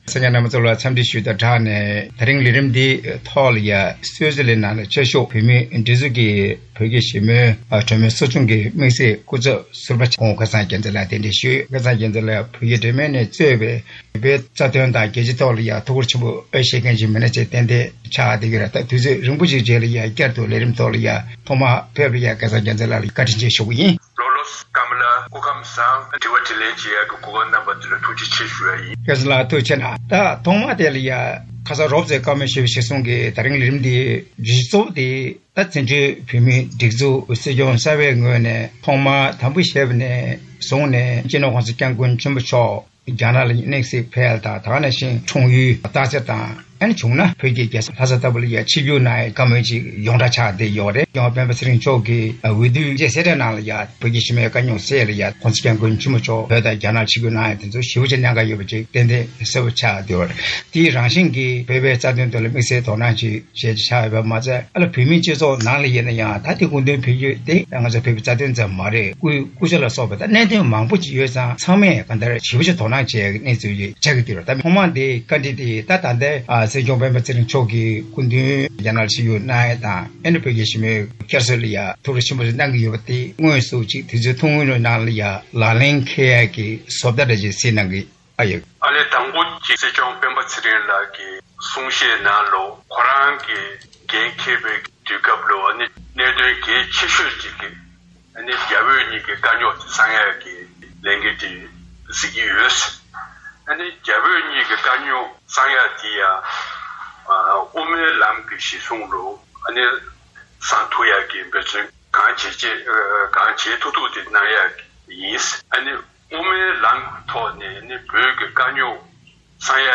ནུབ་ཕྱོགས་རྒྱལ་ཁབ་ནས་མ་འོངས་བོད་ལ་རྒྱབ་སྐྱོར་གནང་ཕྱོགས་སོགས་ཀྱི་སྐོར་གླེང་མོལ་ཞུས་པ་ཞིག་གསན་རོགས་གནང་།